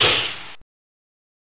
Airlock Door Open, Quick Burst Of Steam